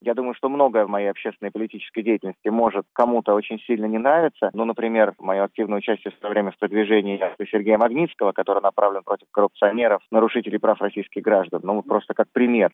Говорит Владимир Кара-Мурза - младший
В интервью Радио Свобода Кара-Мурза-младший связал покушение со своей общественной и политической деятельностью.